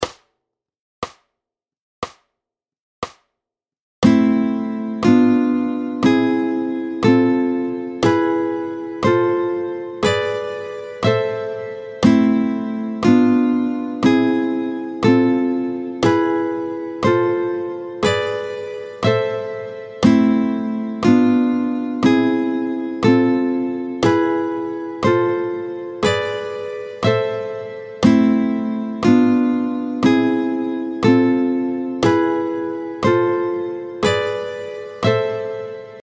24-04 Les 7 accords à 3 sons construits sur la gamme mineure harmonique, tempo 60